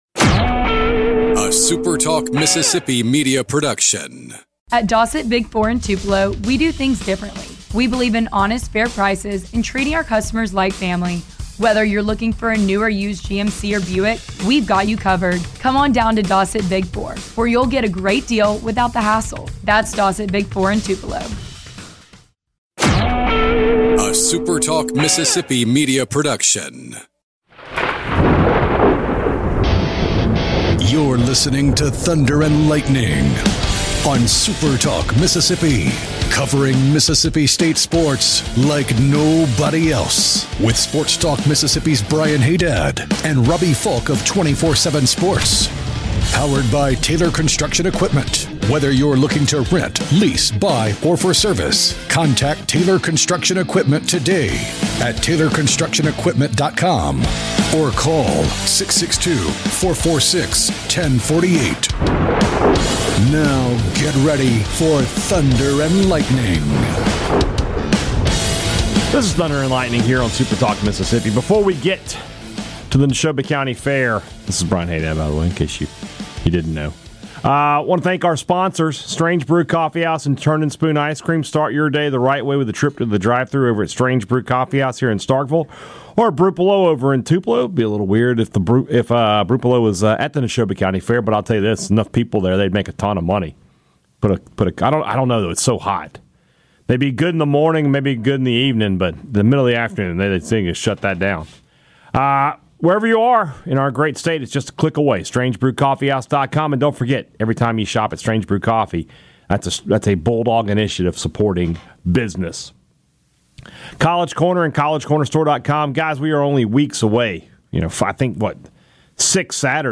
The boys are live at the Neshoba County Fair to talk with a couple of former Bulldogs, a local legend whose son has become a national legend, and a lot more live from admist all the action the fair provides.